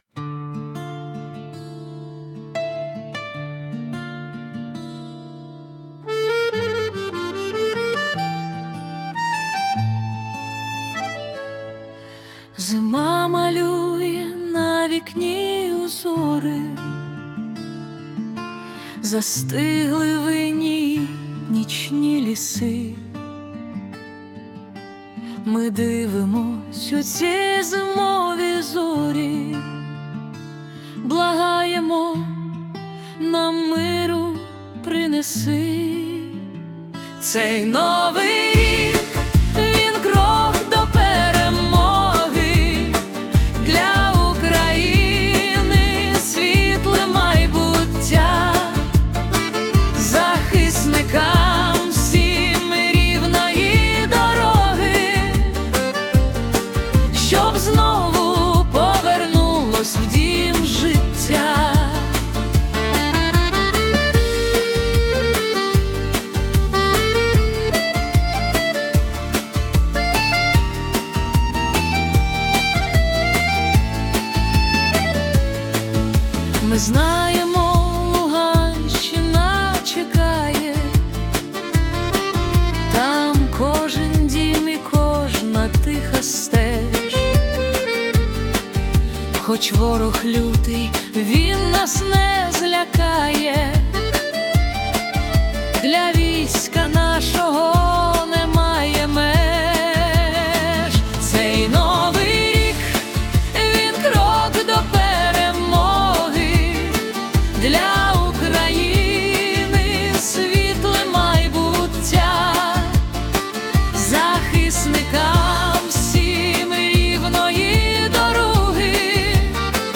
🎵 Жанр: Акустична балада